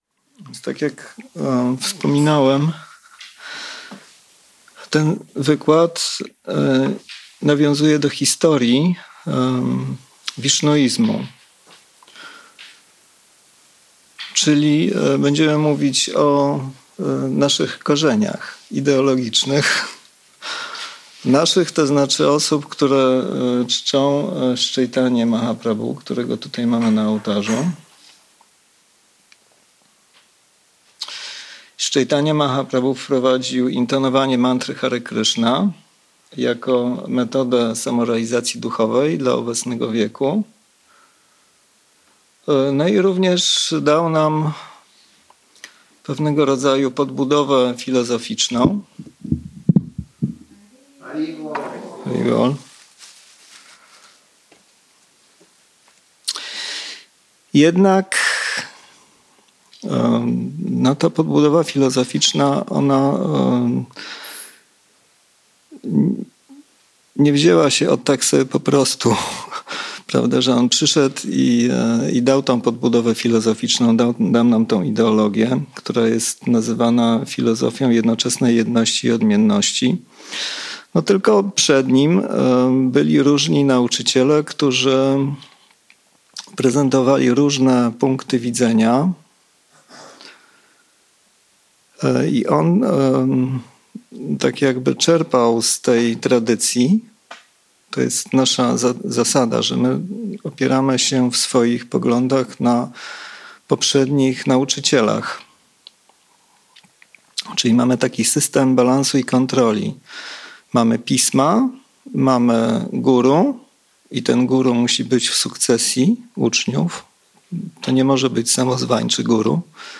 Wykład wygłoszony 15 marca 2026 roku na temat założyciela Rurda sampradayi. Viṣṇusvāmī jest jednym z czterech wielkich ācāryów Vaiṣṇava, którzy ustanowili autentyczne sukcesje uczniów (sampradāye).